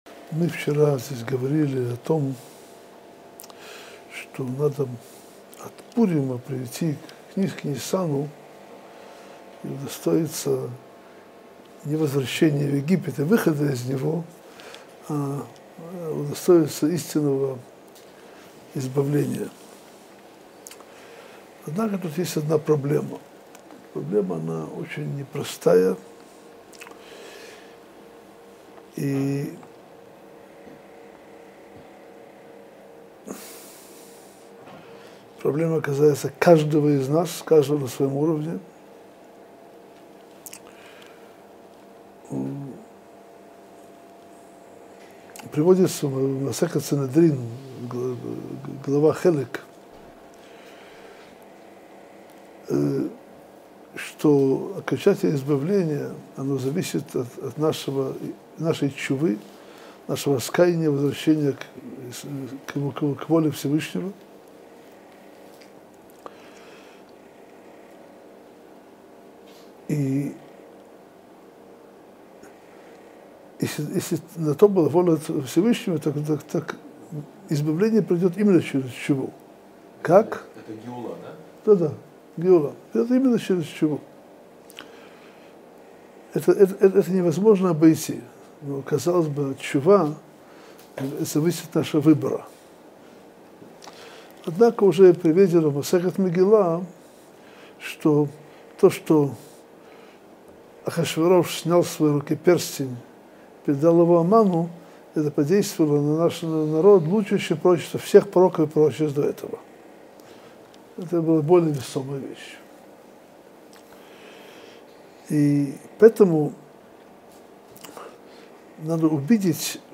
Содержание урока: Какое дело Ирану до того, что происходит в Израиле?